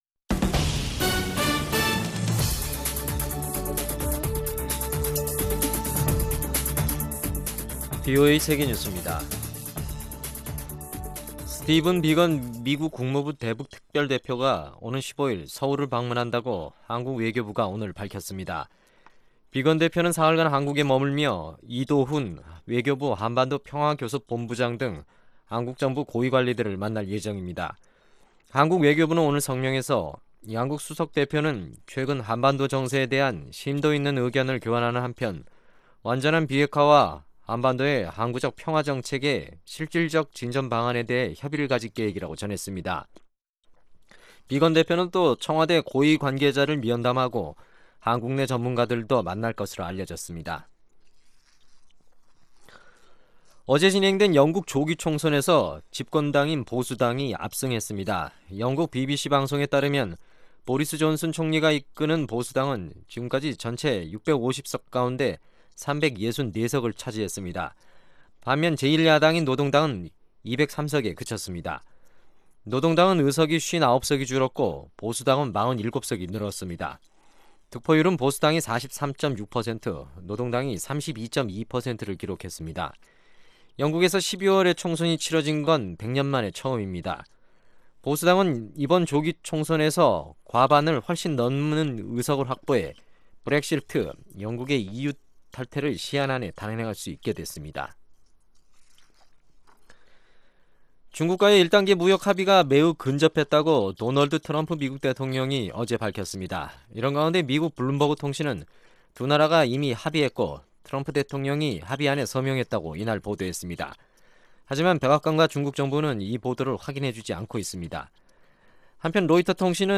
VOA 한국어 간판 뉴스 프로그램 '뉴스 투데이', 2019년 12월 13일 2부 방송입니다. 북한의 “유감스럽고 무분별한 행동"은 더이상 받아들이기 어렵다고 미 국무부 동아태 차관보가 말했습니다. 제 74차 유엔총회에서 북한 핵 관련 결의안 3건이 채택됐습니다.